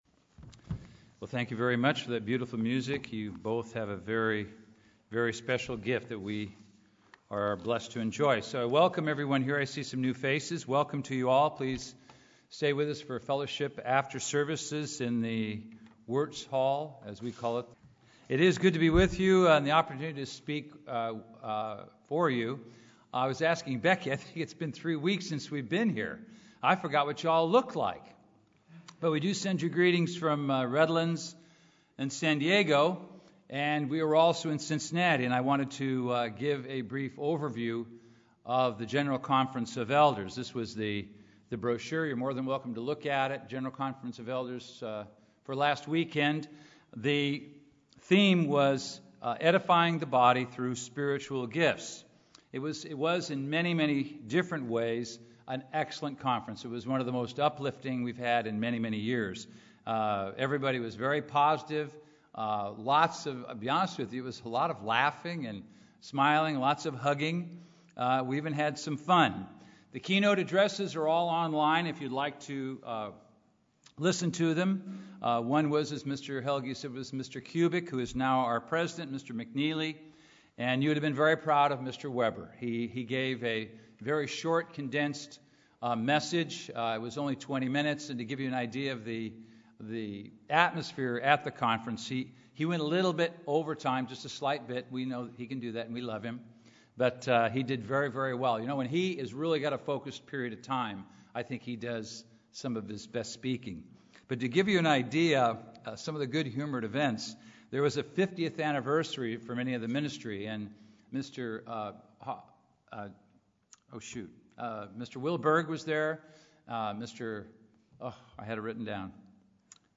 The first of two sermons on spiritual gifts.
Given in Los Angeles, CA